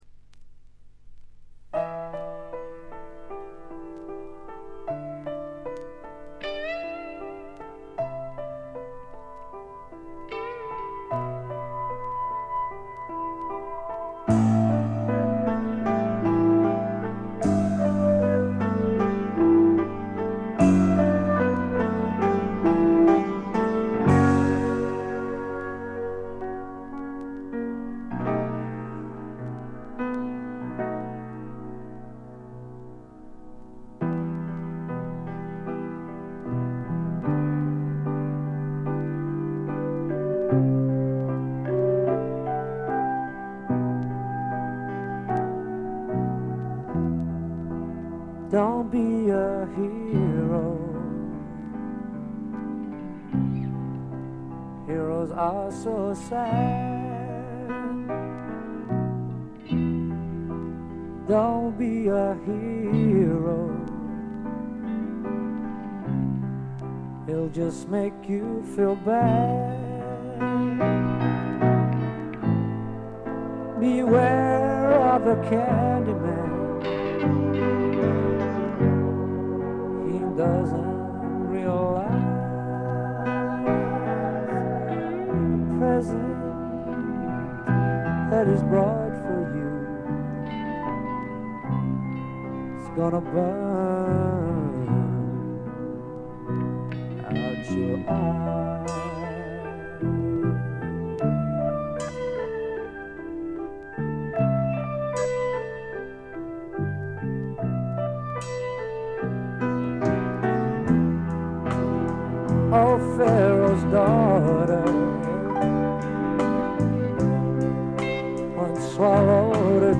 静音部での微細なバックグラウンドノイズ程度。
マッスルショールズ録音の英国スワンプ大名盤です！
試聴曲は現品からの取り込み音源です。